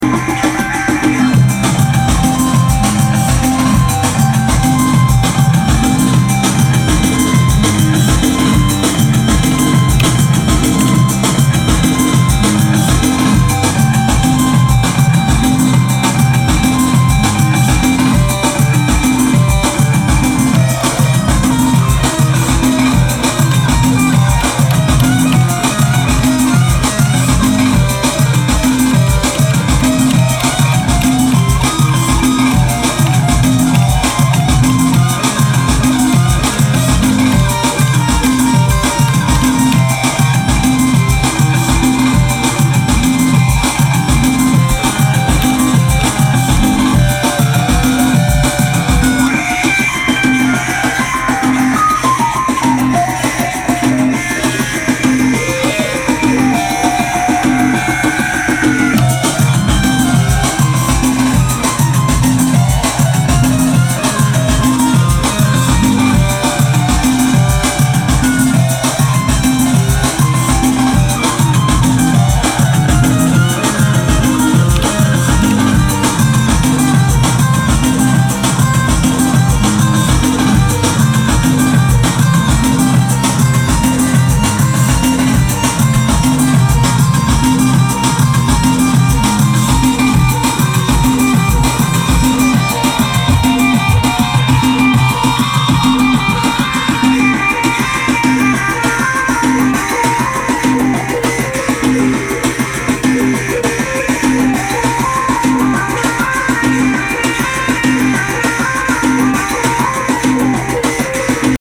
For day 2 I tried to mix my favorite salsa bass line (Fruko - El Preso) with DNB + Santana lead guitar. I got blisters in my fingers from recording the bass lines…
Phone audio to keep it real and lo-fi…
It sits nicely in there.